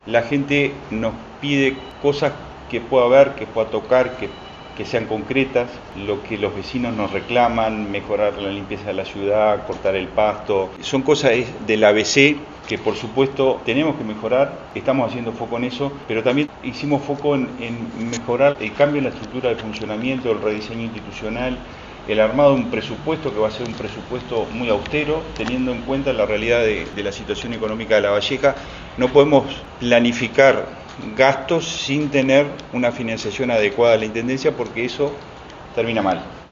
El intendente de Lavalleja, Daniel Ximénez, realizó un balance de los primeros meses de su gestión y describió como “austero” el próximo presupuesto de la comuna.
Informe
XIMENEZ-PRESUPUESTO.mp3